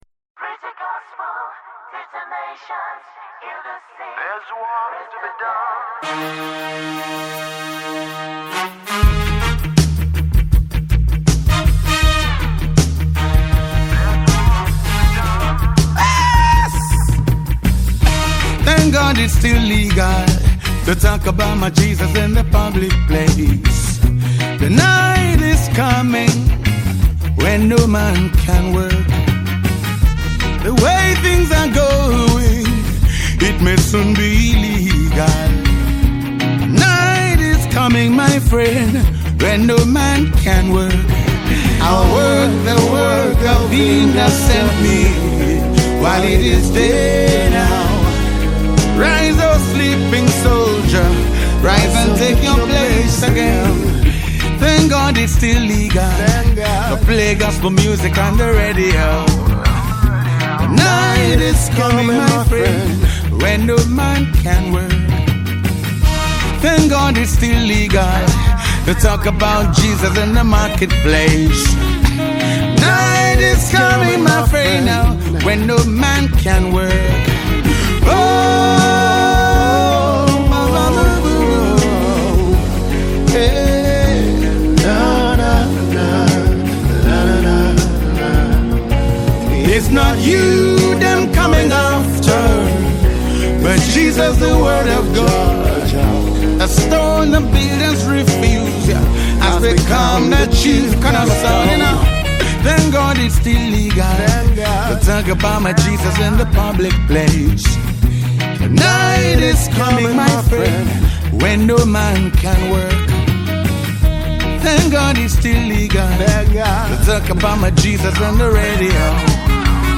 March 12, 2025 Publisher 01 Gospel 0
poet spoken words and reggae gospel artist.